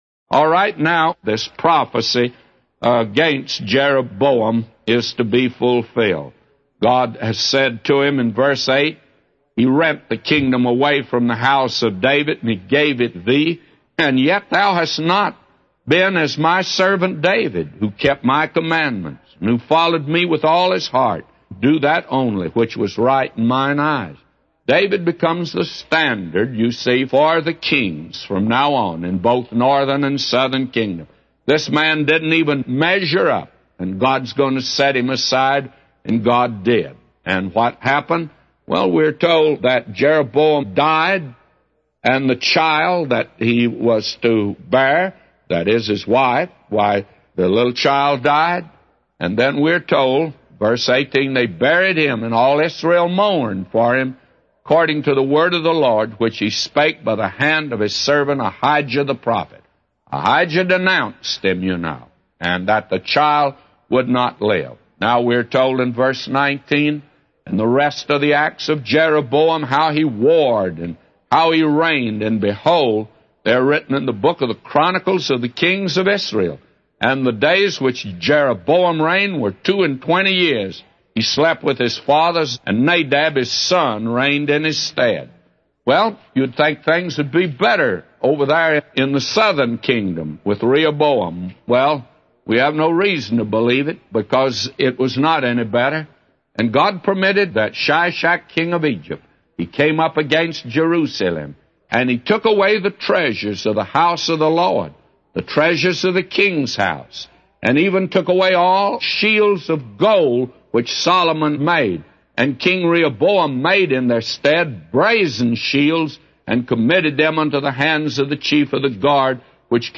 A Commentary By J Vernon MCgee For 1 Kings 14:1-999